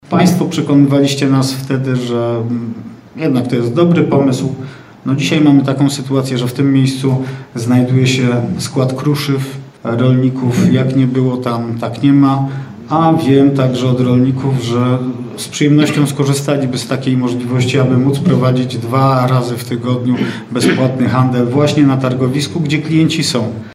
Byłem wtedy zdania, że przyzwyczajenia klientów spowodują, że raczej nie wybiorą się w tamto miejsce – mówił na sesji Leśny.